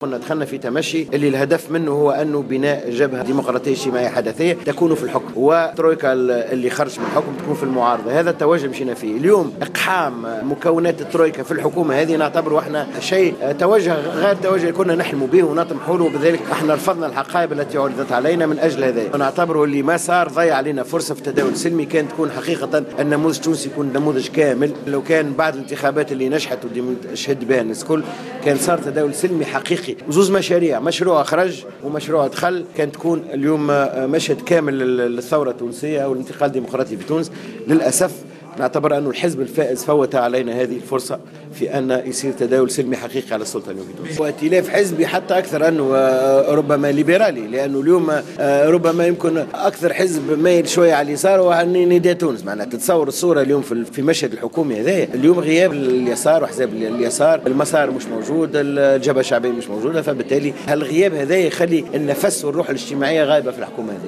اعتبر الأمين العام لحزب المسار سمير الطيب في تصريح لجوهرة "اف ام" على هامش ندوة صحفية للحزب خصصت لتقديم موقفه من الحكومة الجديدة أن حكومة الحبيب الصيد أقصت اليسار.